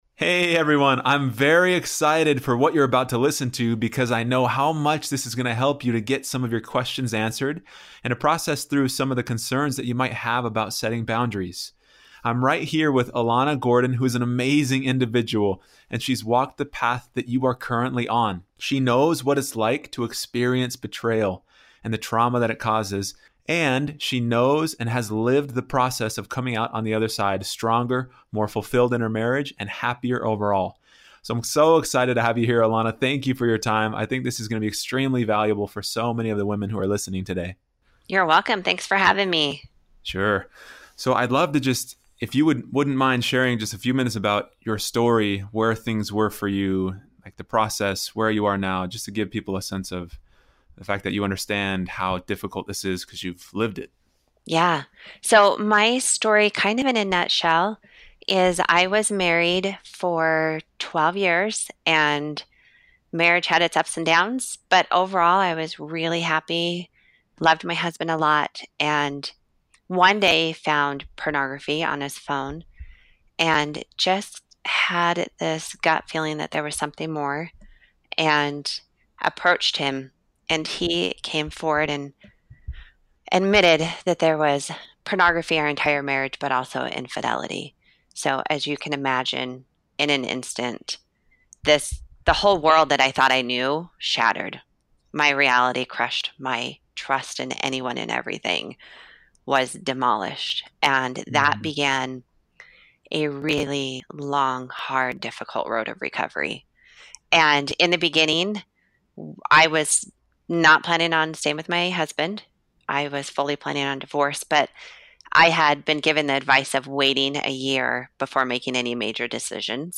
Bonus 3 - a marriage restored (interview)